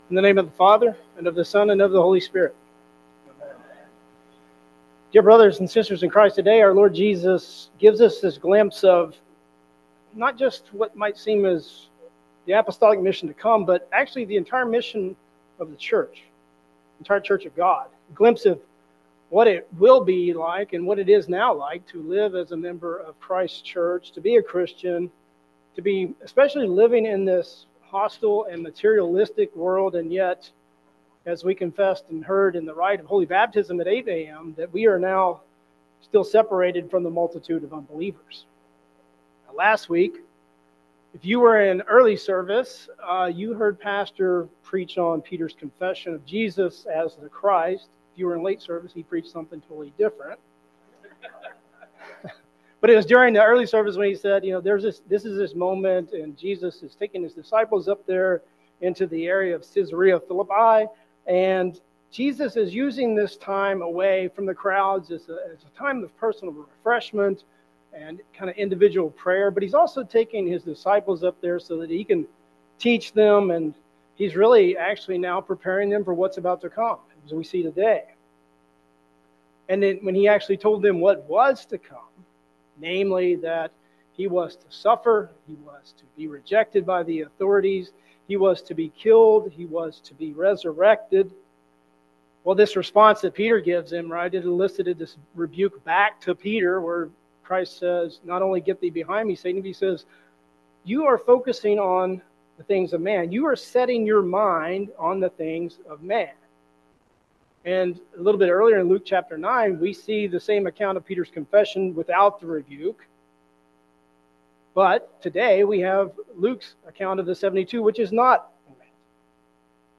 Sermon for Fourth Sunday after Pentecost